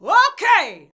O.K.     1.wav